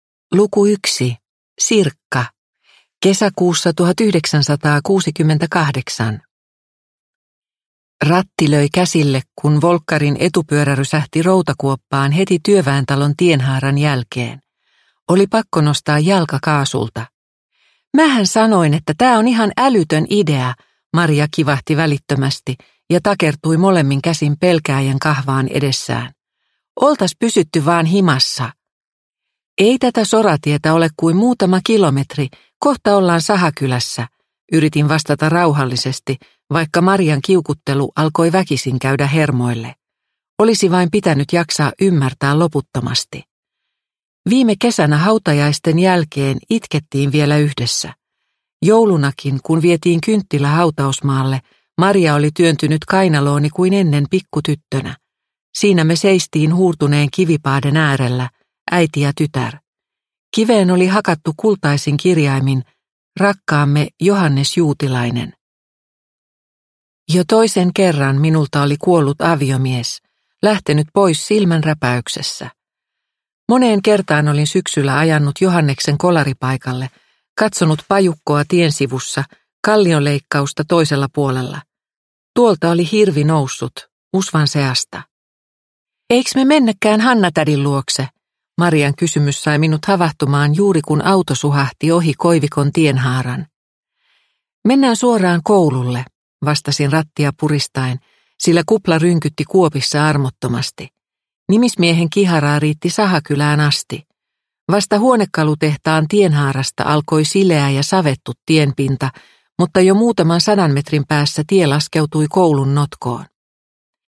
Unikkoja ikkunassa – Ljudbok – Laddas ner